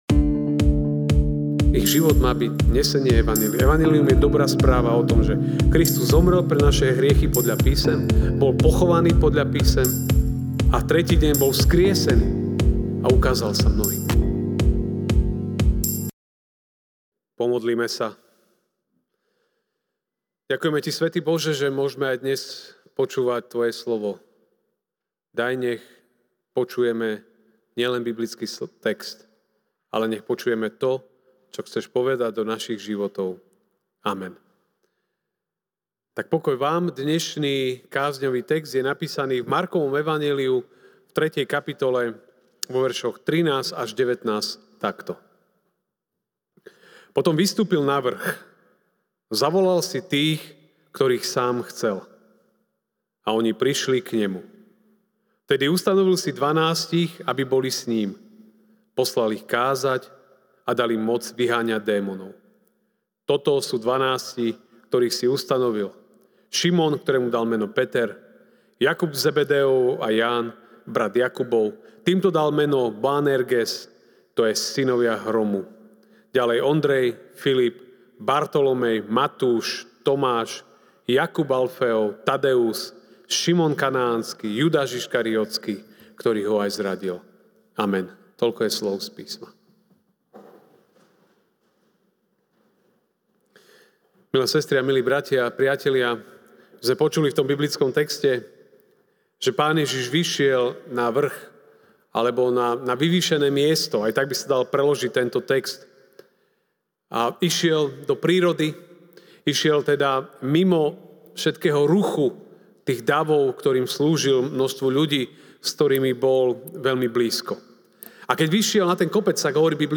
jún 30, 2024 Najlepší plán pre život MP3 SUBSCRIBE on iTunes(Podcast) Notes Sermons in this Series Večerná kázeň: Mk(3, 13-19) „ Potom vystúpil na vrch, zavolal si tých, ktorých sám chcel.